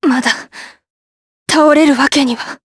Tanya-Vox_Dead_jp.wav